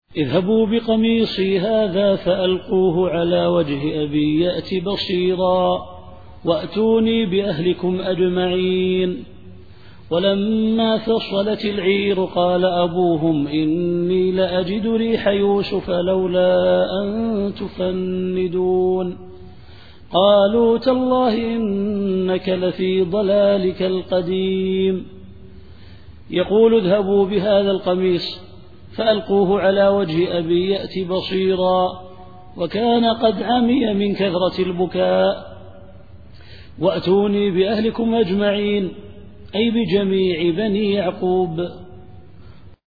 التفسير الصوتي [يوسف / 93]